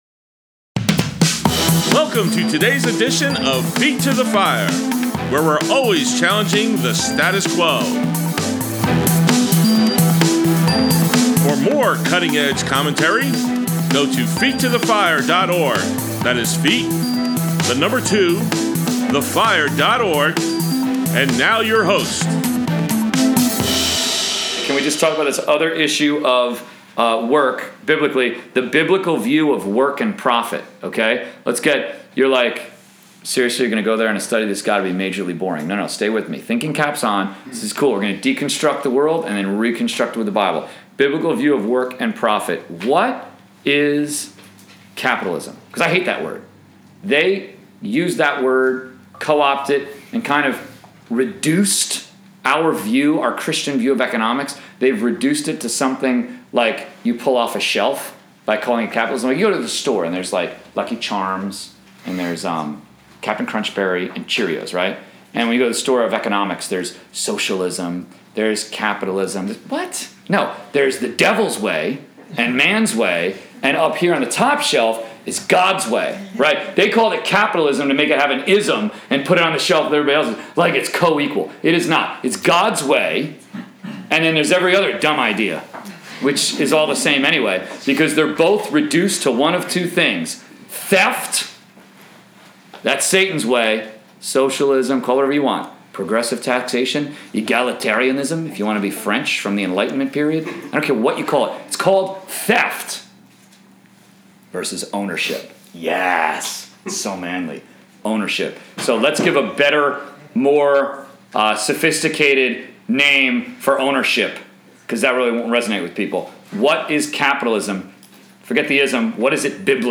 College/Career Bible Study, April 1, 2017: Part 2 of 3